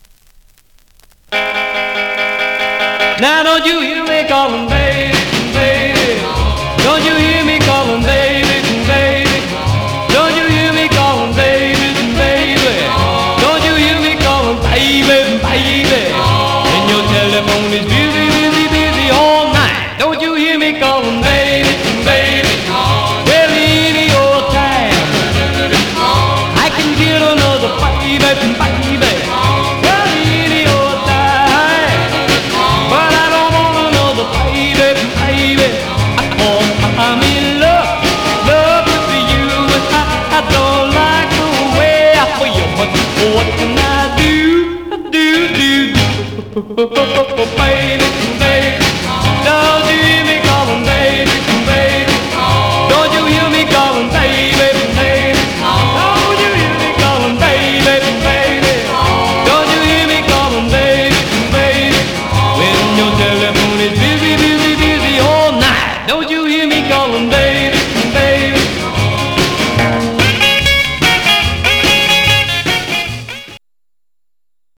Some surface noise/wear Stereo/mono Mono
Rockabilly